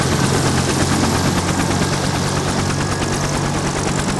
propellersStart.wav